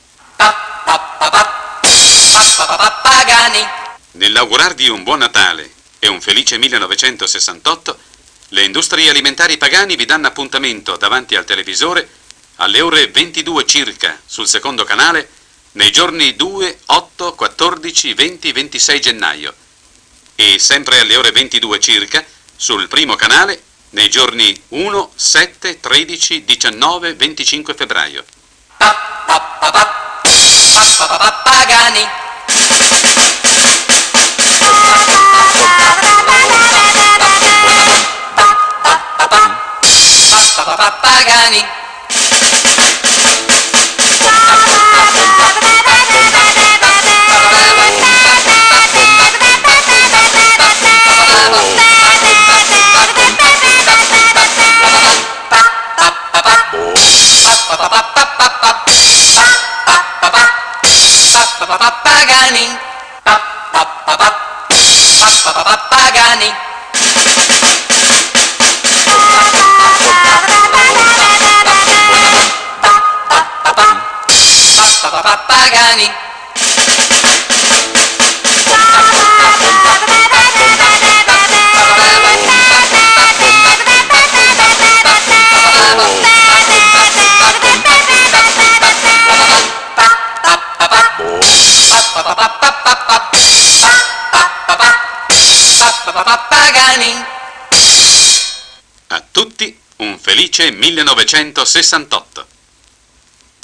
Christmas and New Year 1968 greetings Announcement: Advertising campaign on TV, January-February 1968